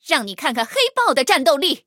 黑豹开火语音1.OGG